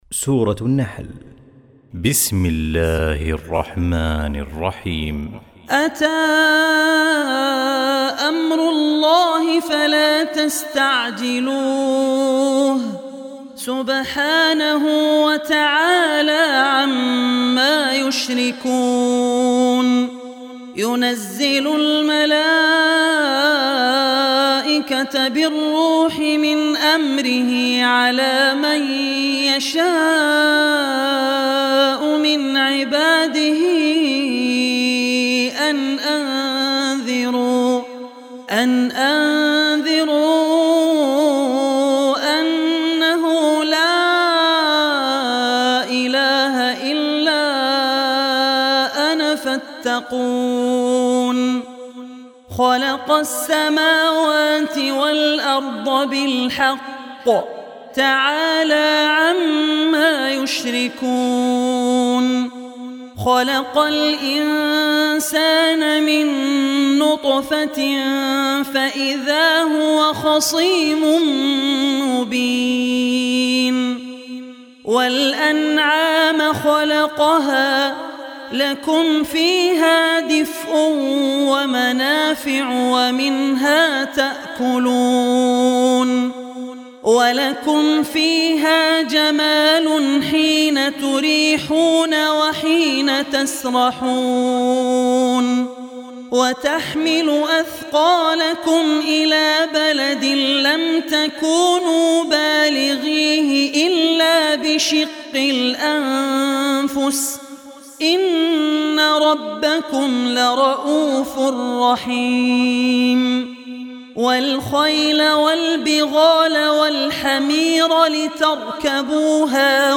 16-surah-nahl.mp3